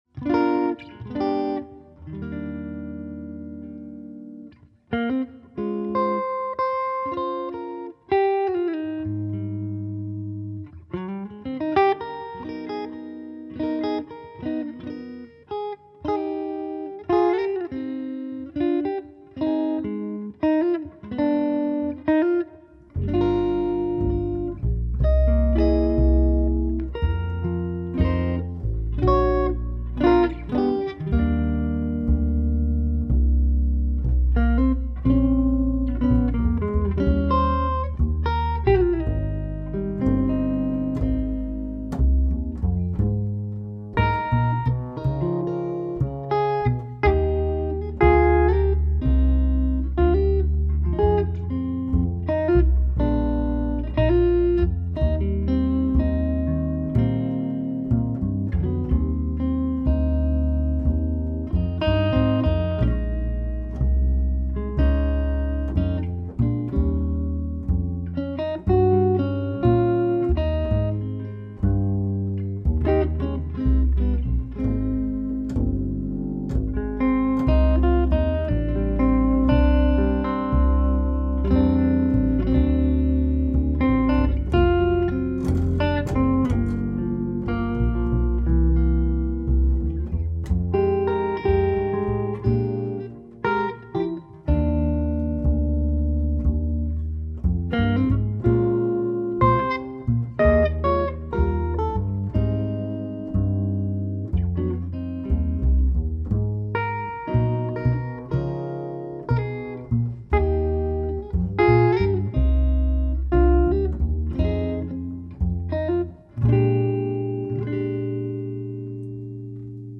Melodisk og swingende jazz.
jazzguitar, saxofon og kontrabas
• Jazzband